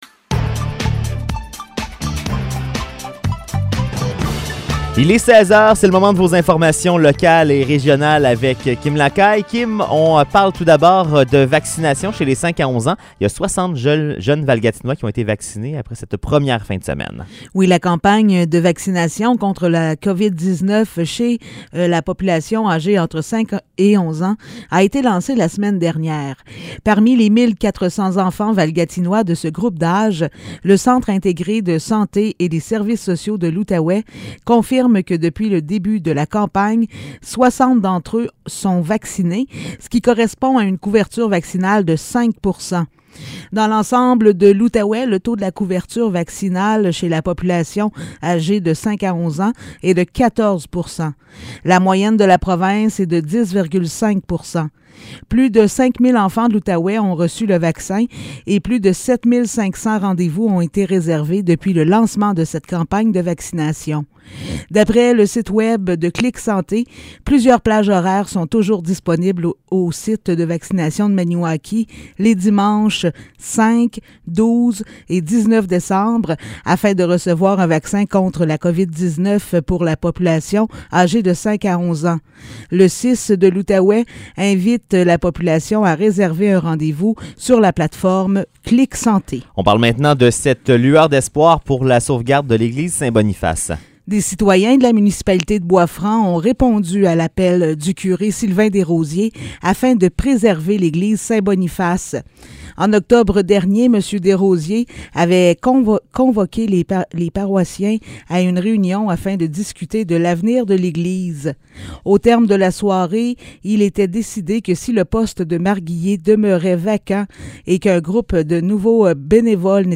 Nouvelles locales - 29 novembre 2021 - 16 h